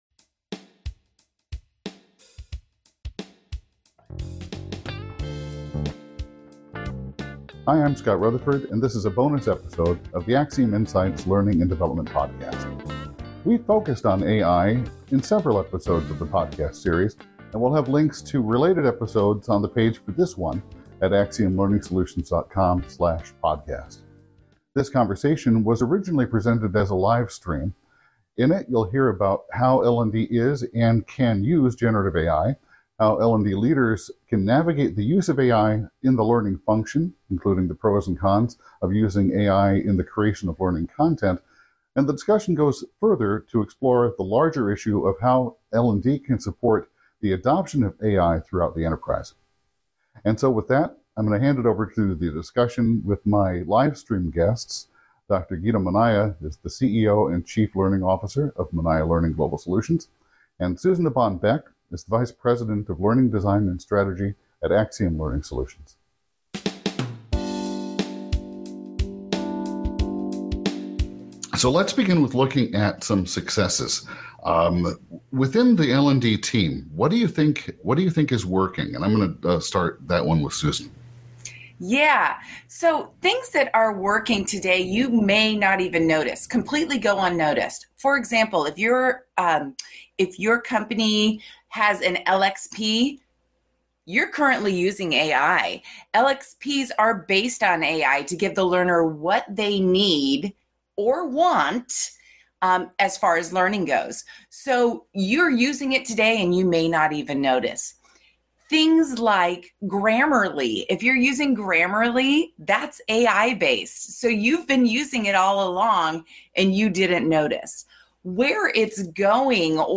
In this bonus episode of the podcast, we are resharing a recording from a live stream discussion.